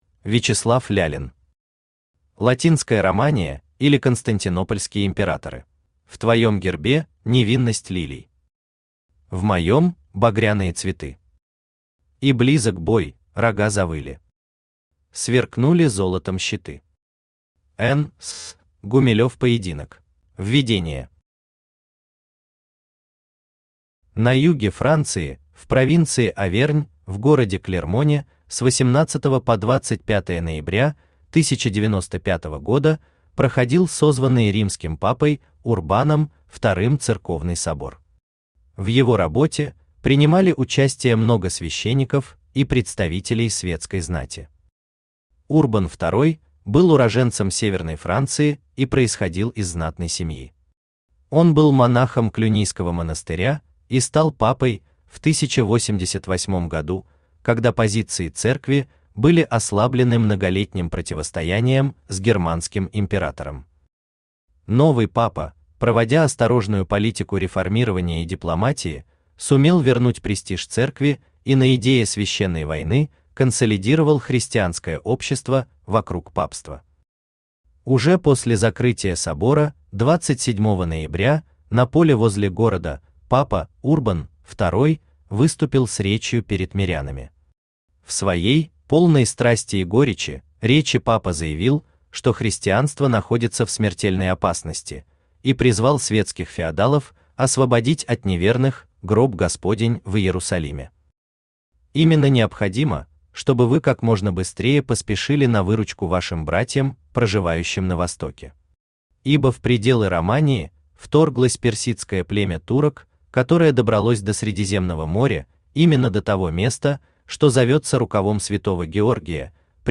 Аудиокнига Латинская Романия, или Константинопольские императоры | Библиотека аудиокниг
Aудиокнига Латинская Романия, или Константинопольские императоры Автор Вячеслав Егорович Лялин Читает аудиокнигу Авточтец ЛитРес.